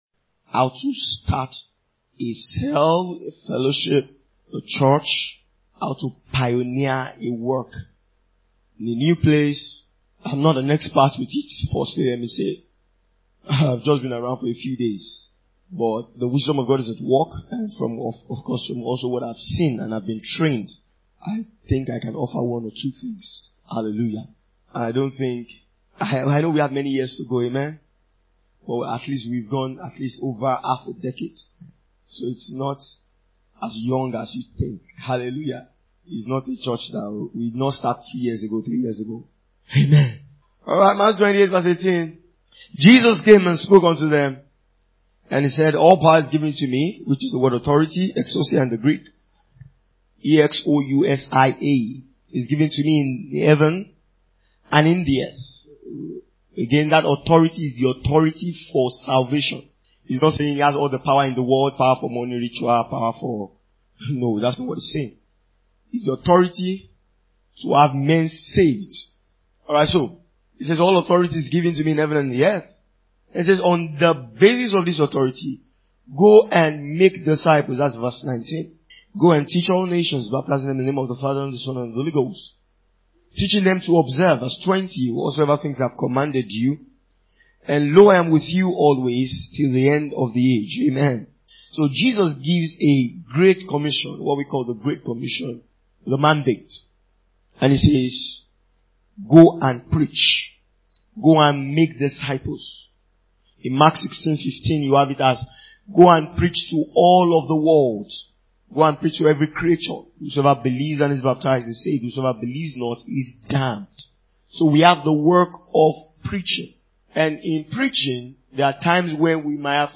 Starting a new cell (Workers’ Meeting 2022)